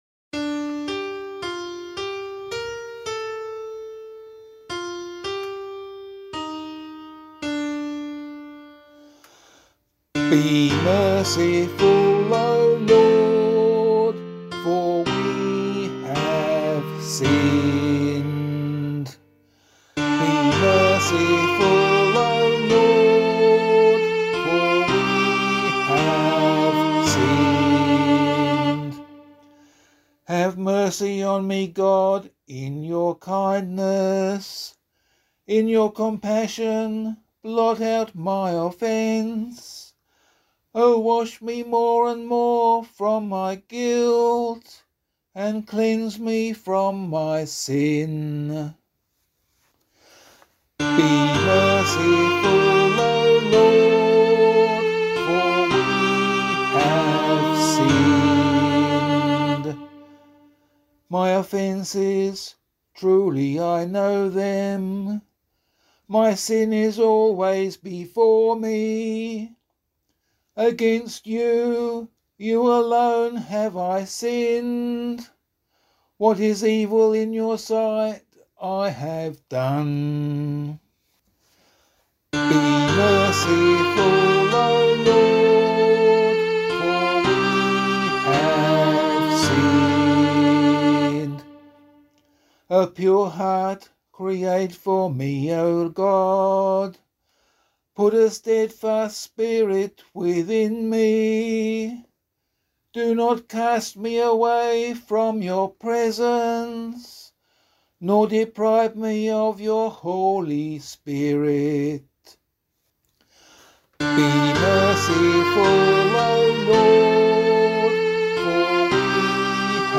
012 Ash Wednesday Psalm [LiturgyShare 3 - Oz] - vocal.mp3